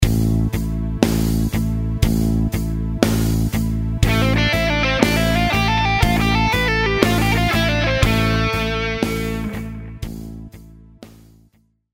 Guitar Lick 8 – A Natural Minor Lick – Position 2.
Audio Sample Lick 8 Slow – 60BPM
Lick8_60bpm.mp3